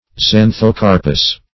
Search Result for " xanthocarpous" : The Collaborative International Dictionary of English v.0.48: Xanthocarpous \Xan`tho*car"pous\, a. [Xantho- + Gr. karpo`s fruit.]